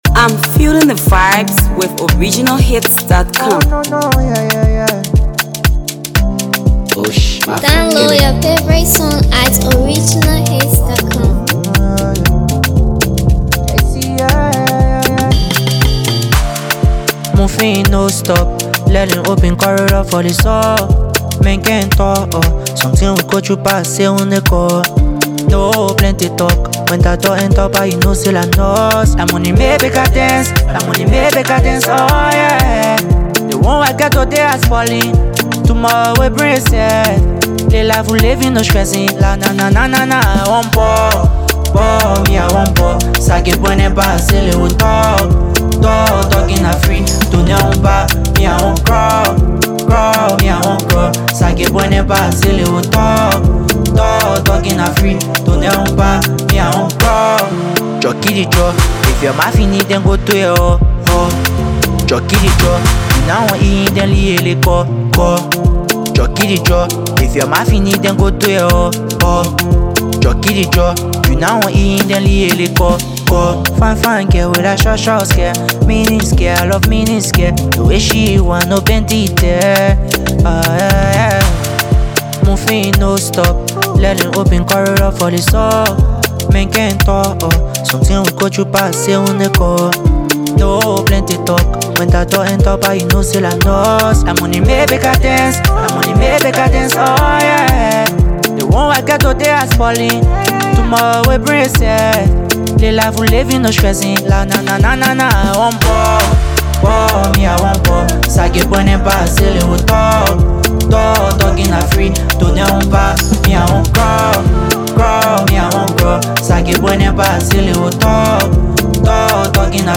masterpiece banger an irresistible jam